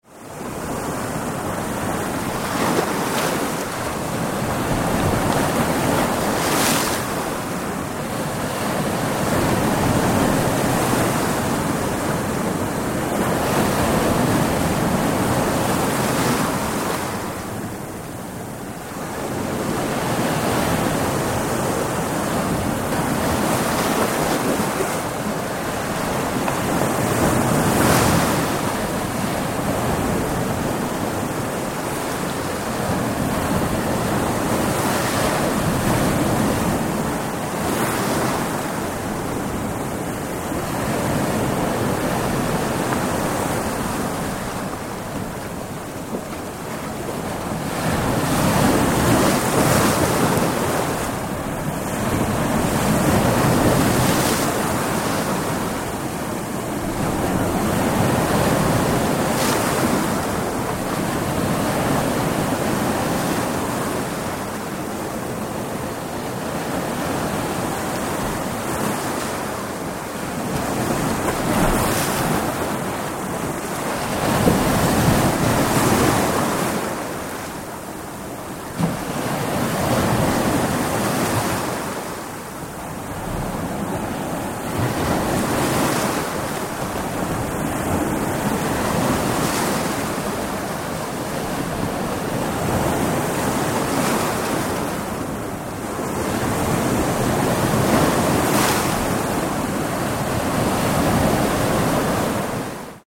Stormy-sea-sound-effect.mp3